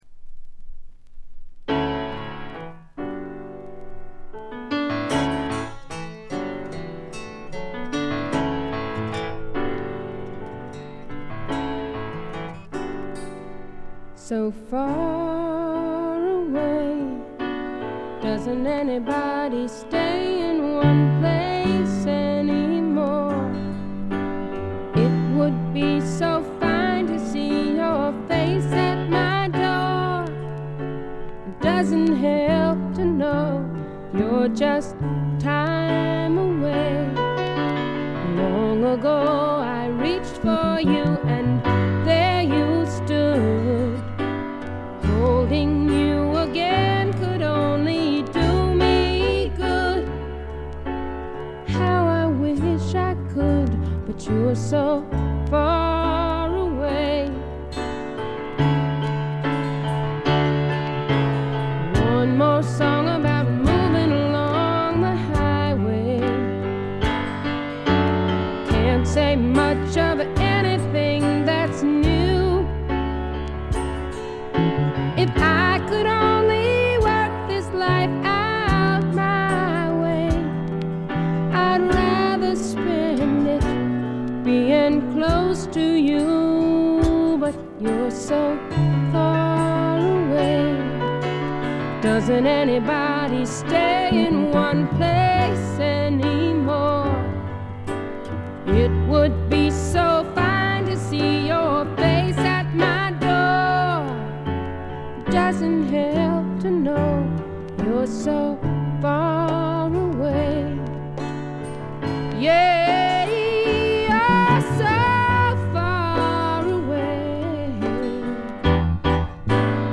A2終盤で周回ノイズ。
試聴曲は現品からの取り込み音源です。